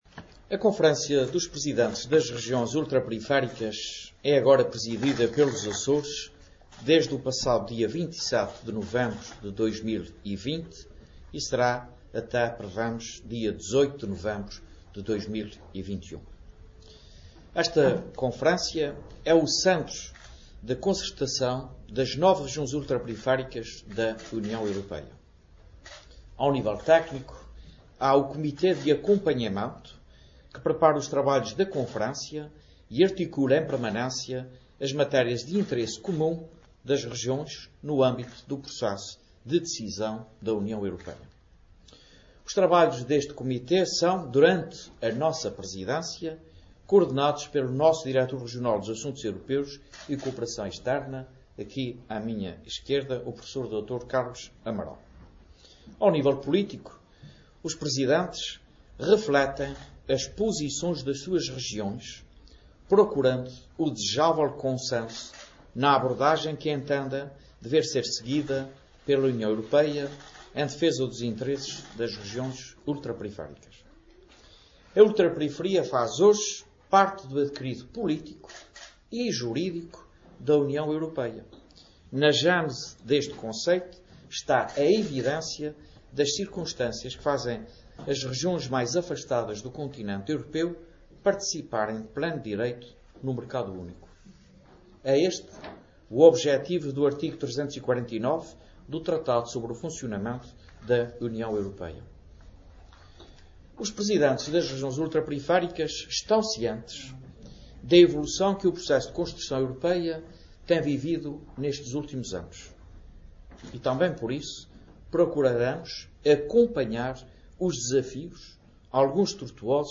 José Manuel Bolieiro presents programme of the Azores presidency for the Conference of Presidents of the Outermost Regions